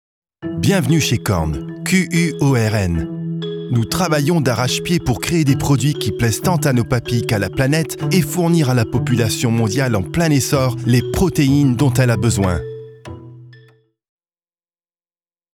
bilingual French and English voice-over actor with a medium, smooth voice that suits 25-35 yo professional, educated male. He is at ease in both languages without a trace of an accent.
Sprechprobe: Werbung (Muttersprache):
Quorn - corporate presentation_0.mp3